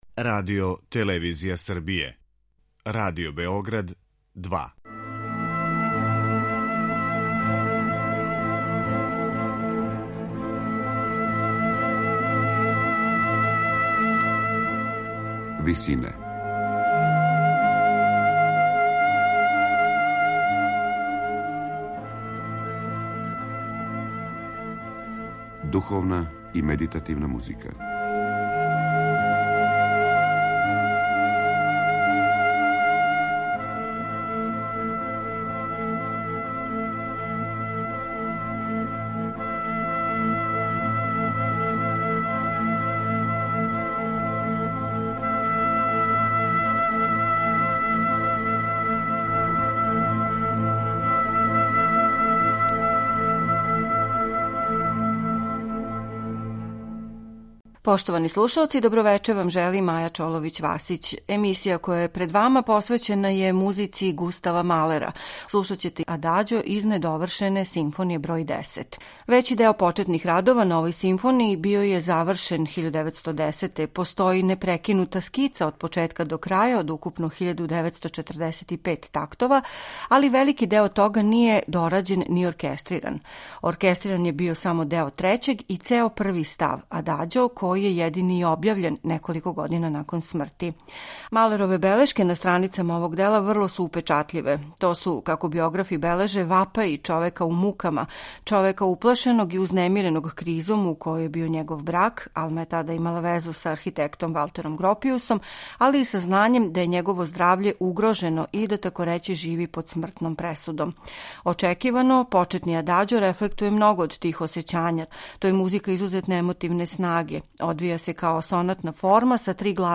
Њујоршка филхармонија